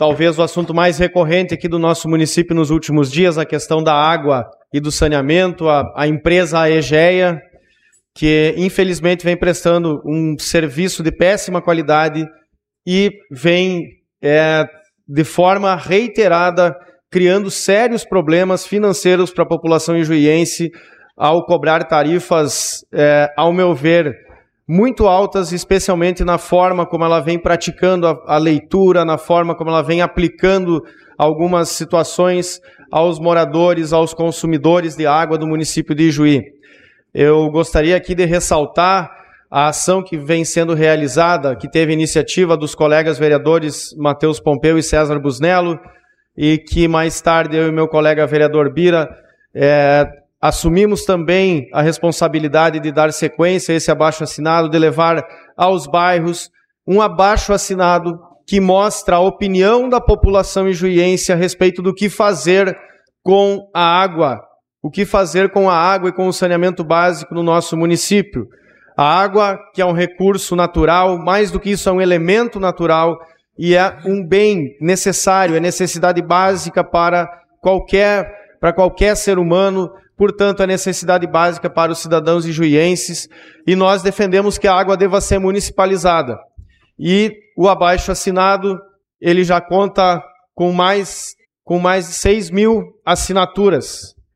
Durante o pequeno expediente da sessão legislativa de ontem na Câmara de Vereadores de Ijuí, o vereador do Partido dos Trabalhadores, Rudimar Scheren, destacou a abertura da comissão especial que irá tratar do tema do saneamento no município.